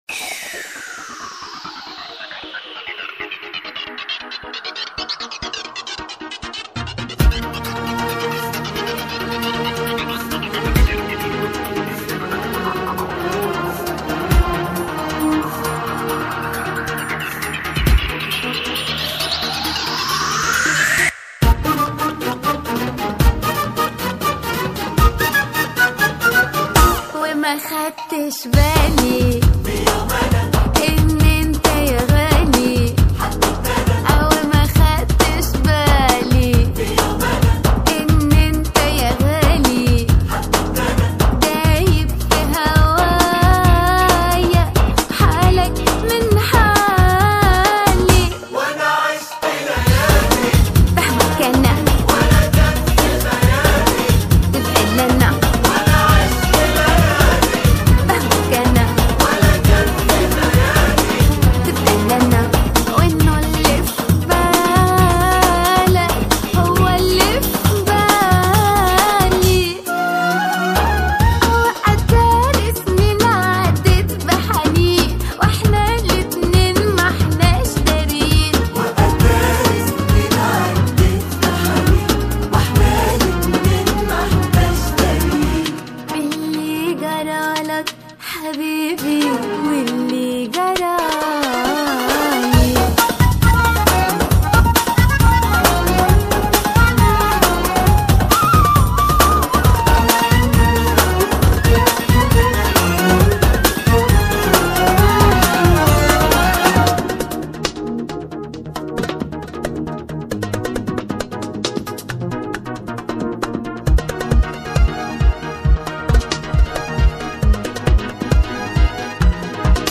это зажигательная арабская поп-песня